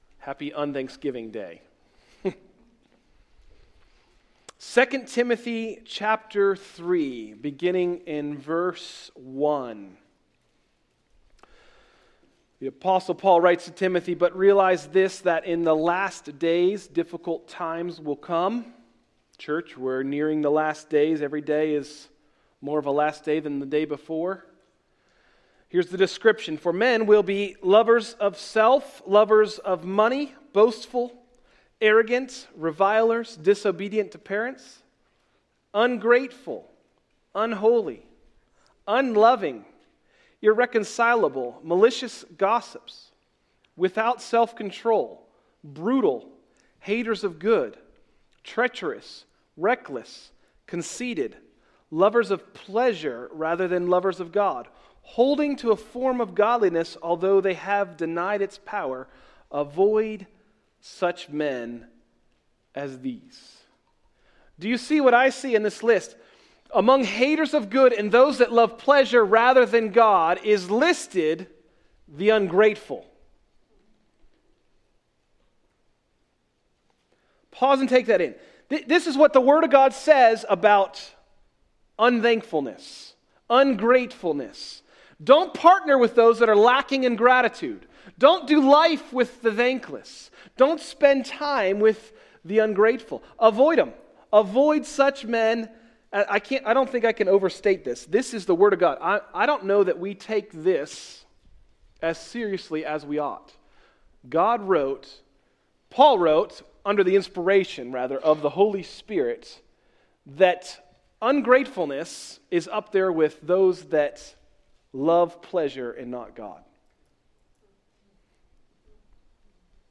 Message: “Unthanksgiving” – Tried Stone Christian Center